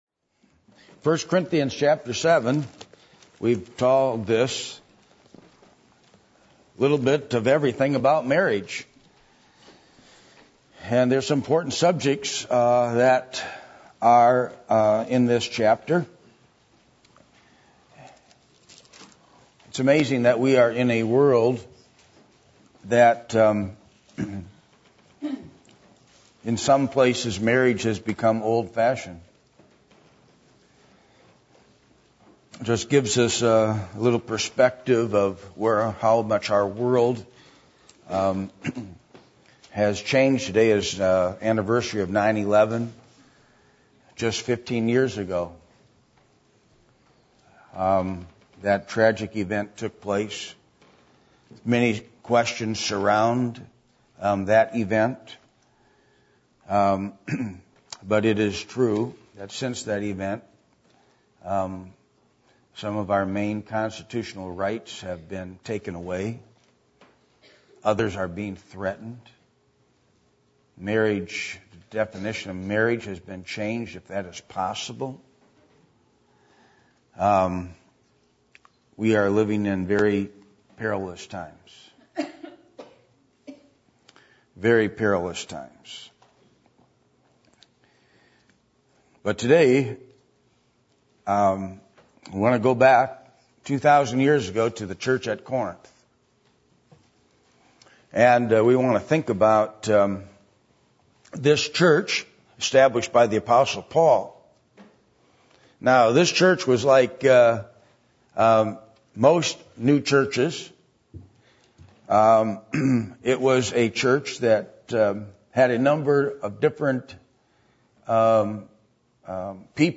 Passage: 1 Corinthians 7:10-27 Service Type: Sunday Morning %todo_render% « What Level Is Your Knowledge Of God?